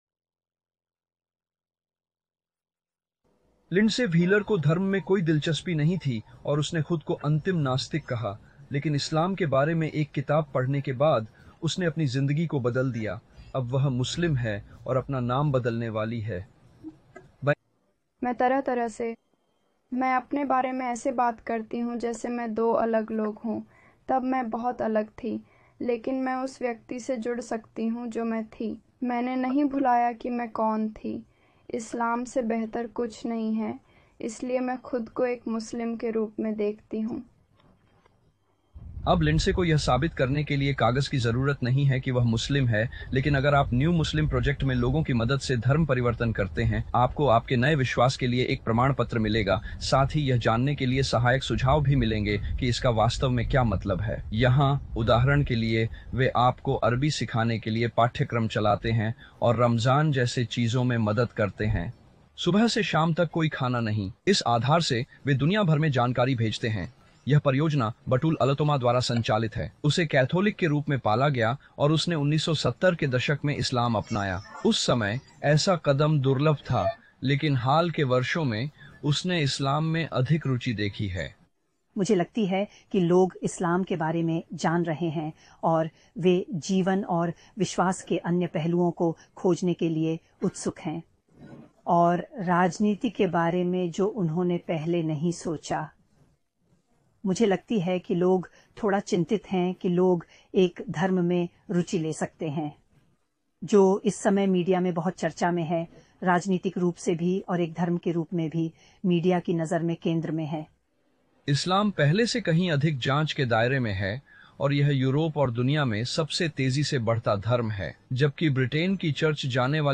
विवरण: इस 5 न्यूज़ ब्रॉडकास्ट में बताया गया है कि यूके और पूरे यूरोप में इस्लाम सबसे तेजी से फैलने वाला धर्म कैसे बन गया।